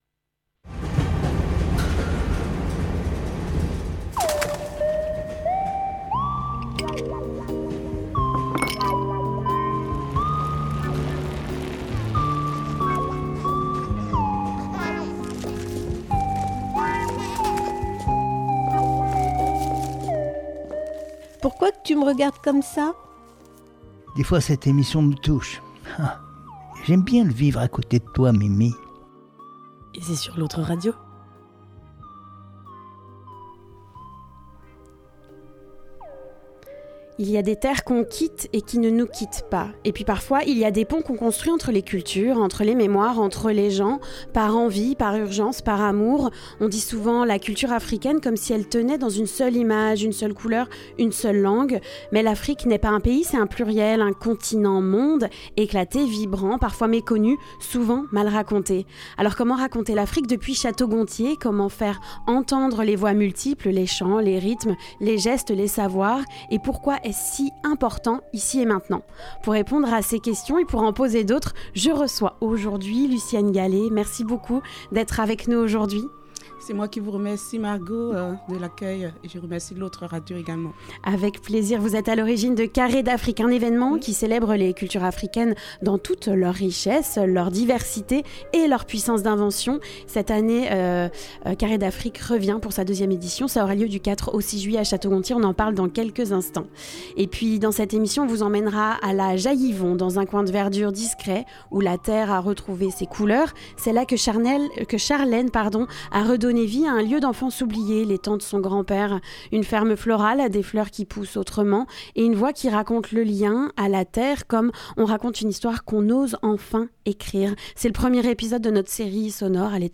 La revue de presse du Haut Anjou
La revue de presse du Haut Anjou Le reportage - épisode 1 : Revenir à l’étang C'est notre premier reportage au coeur de la ferme florale L'Etang de Papi à La Jaille-Yvon.